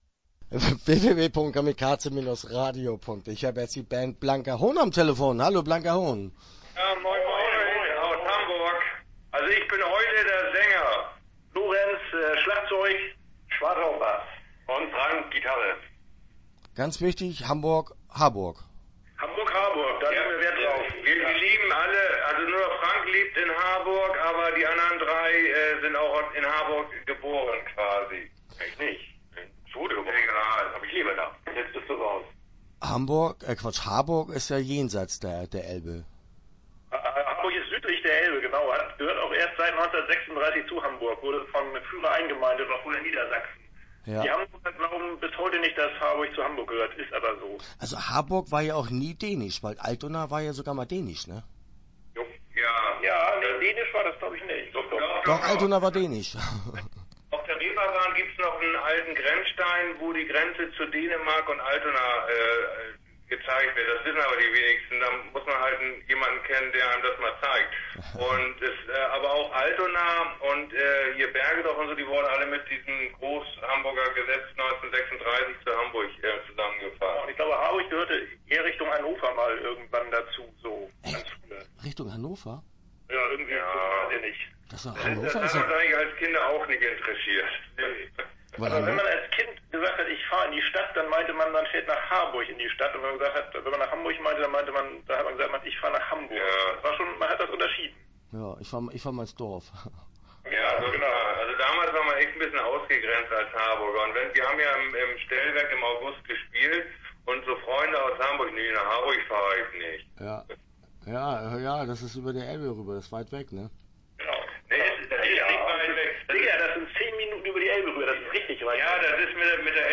Blanker Hohn - Interview Teil 1 (11:44)